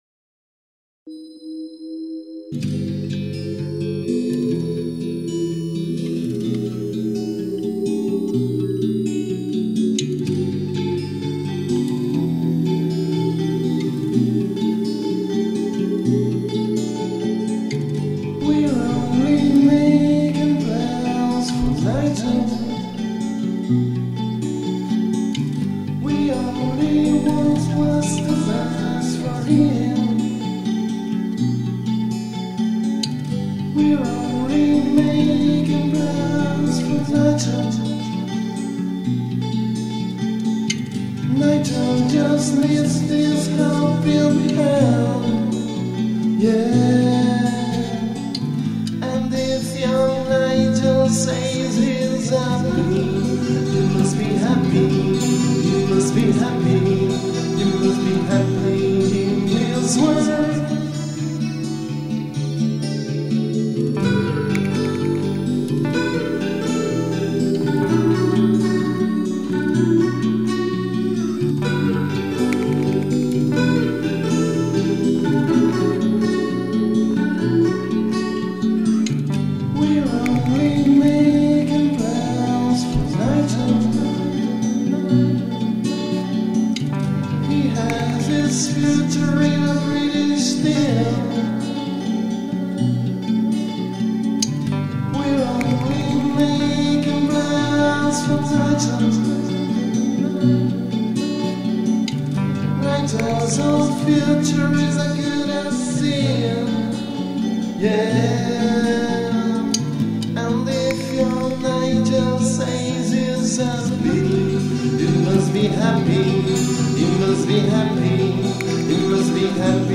guitar, keyboards and voices
drums and bass